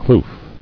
[kloof]